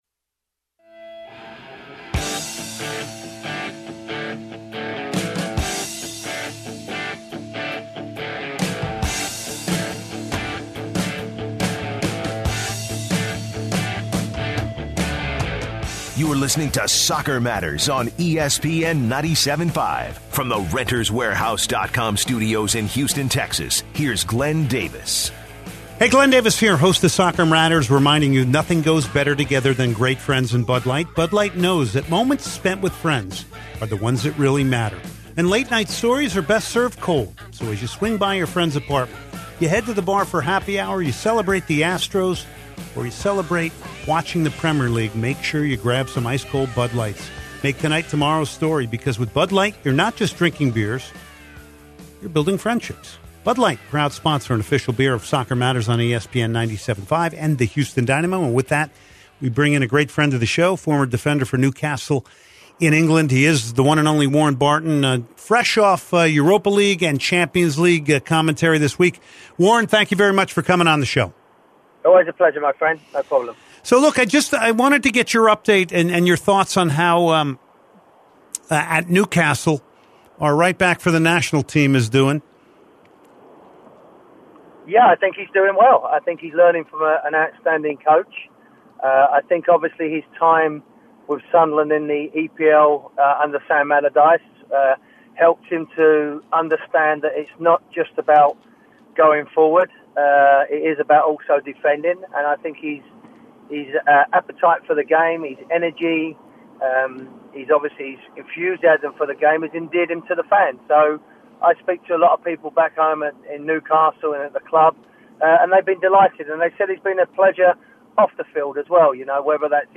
interviews Warren Barton, retired Newcastle defender